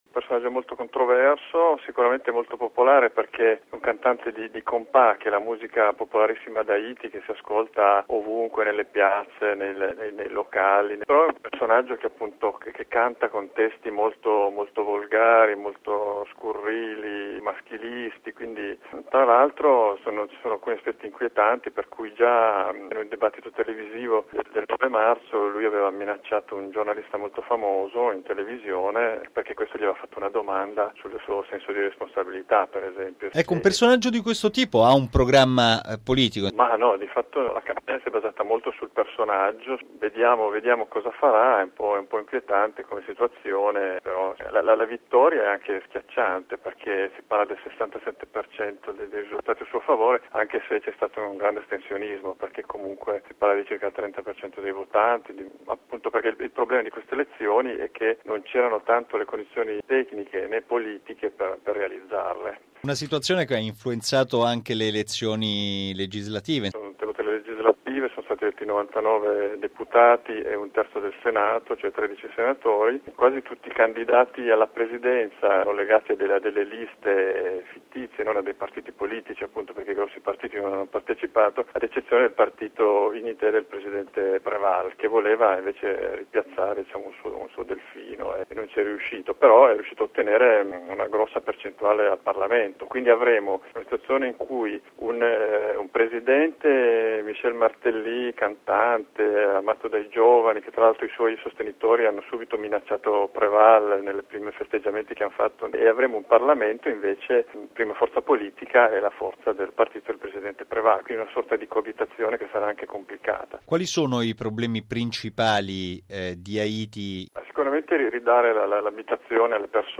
ha intervistato il giornalista